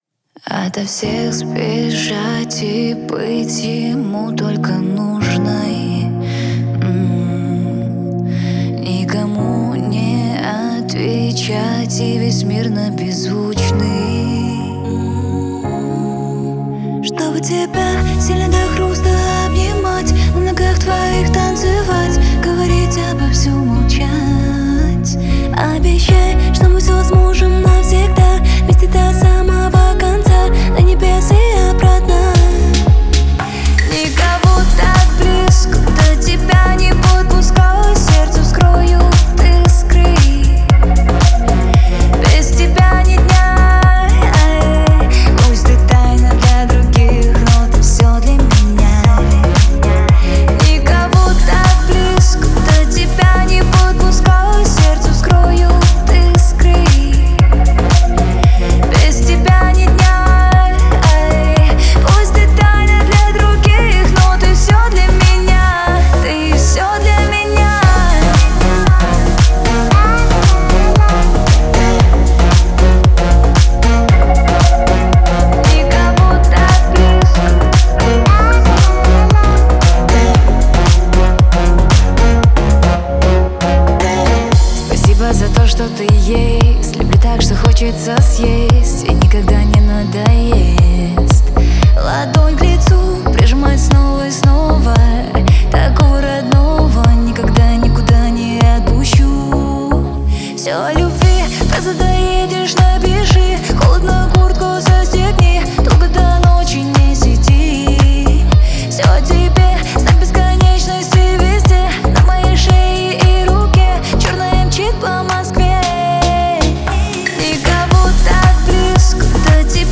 мягкий вокал
мелодичные синтезаторы